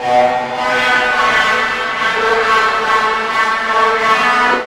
18 GUIT 2 -L.wav